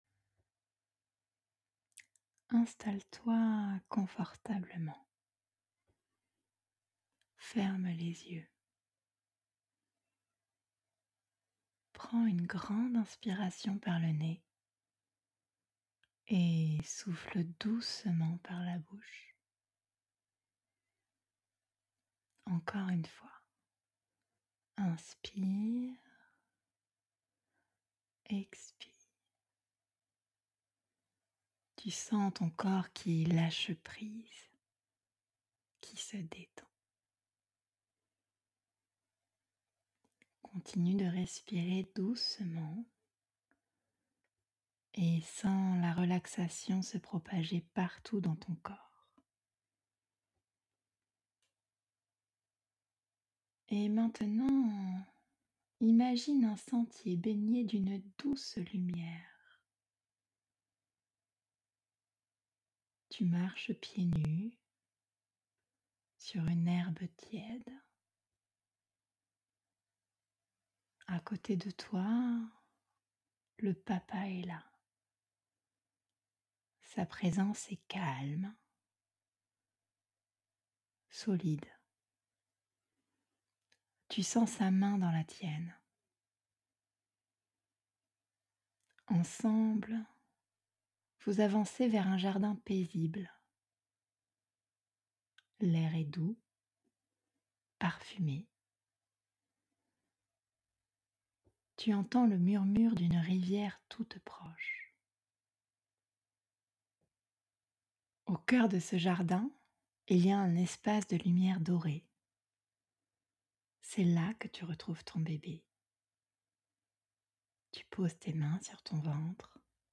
Votre première relaxation guidée